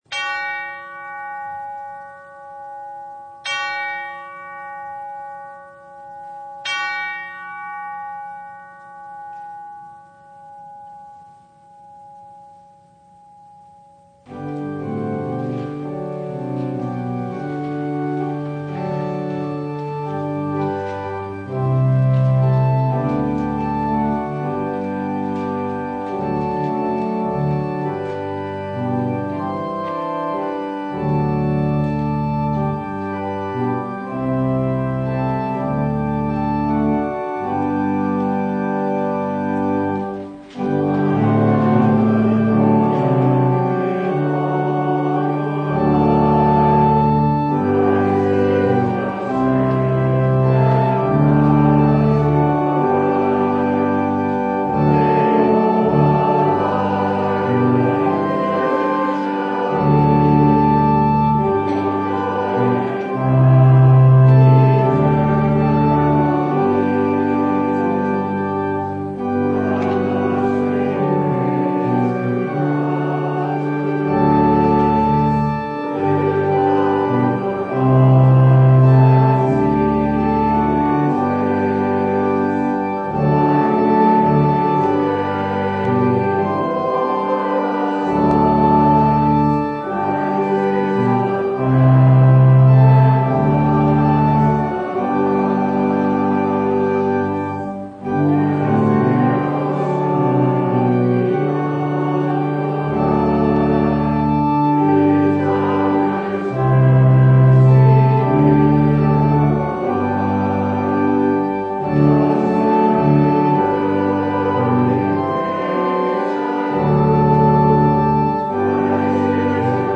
Isaiah 40:21-31 Service Type: Sunday The Lord is our strength and gives us comfort.